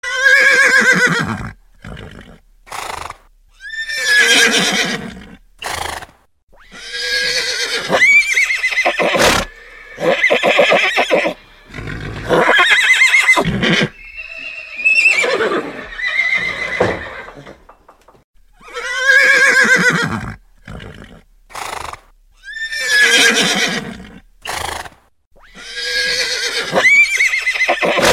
Horses Talking klingelton kostenlos
Kategorien: Tierstimmen